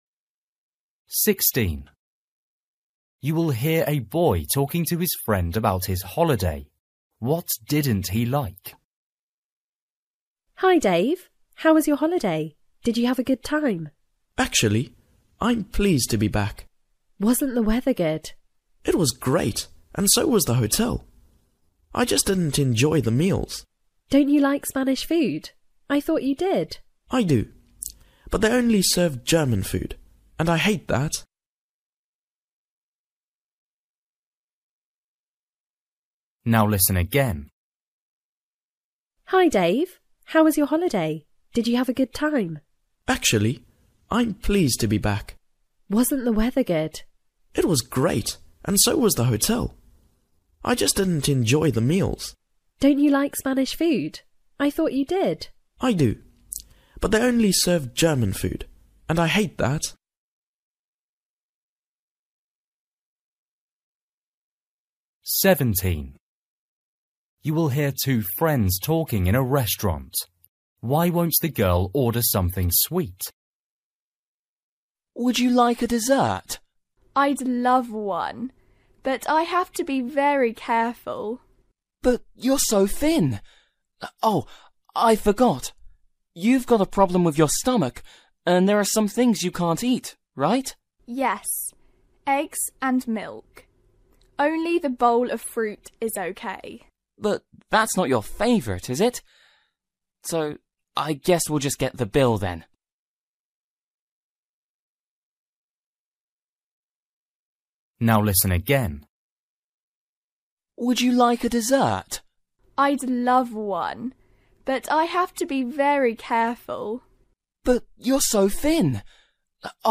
Listening: everyday short conversations
16   You will hear a boy talking to his friend about his holiday. What didn’t he like?
17   You will hear two friends talking in a restaurant. Why won’t the girl order something sweet?
18   You will hear two teachers talking outside a shop. Why is the woman unhappy?
19   You will hear a girl speaking on the phone. What’s the problem with her trainers?
20   You will hear a boy talking to his sister. What’s the problem with his sister’s suitcase?